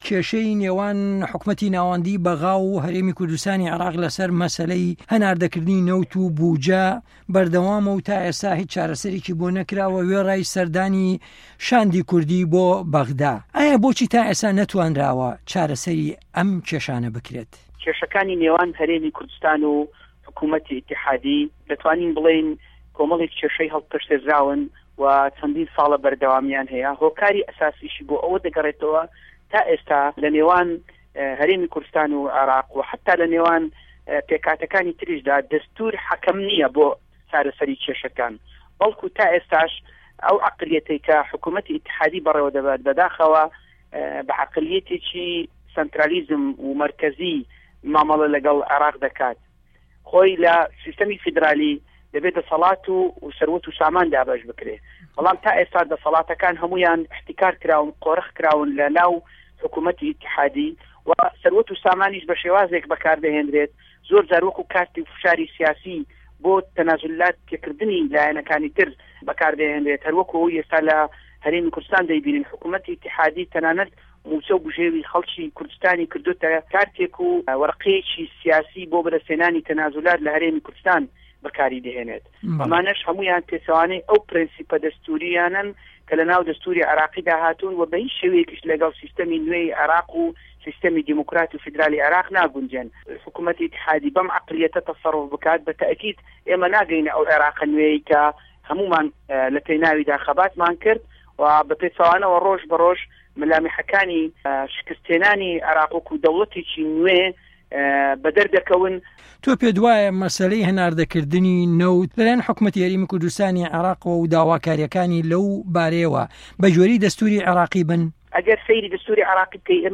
وتووێژی دکتۆر فه‌رسه‌ت سۆفی